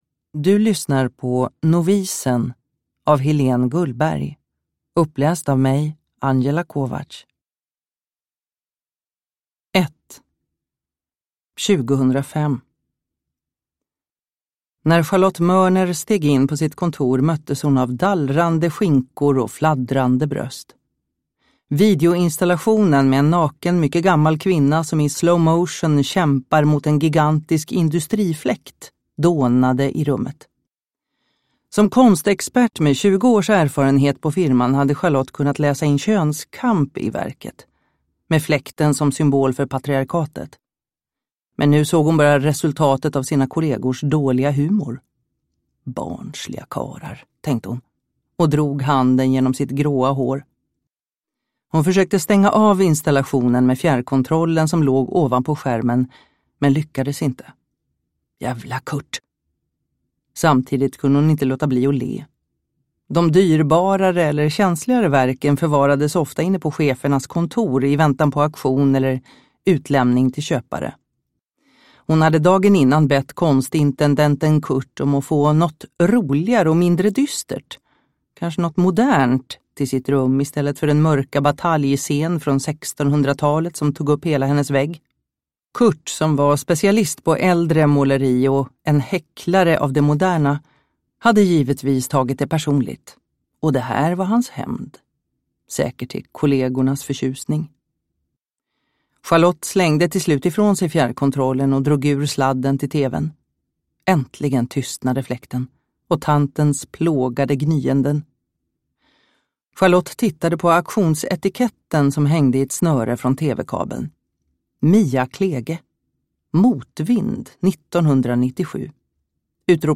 Novisen – Ljudbok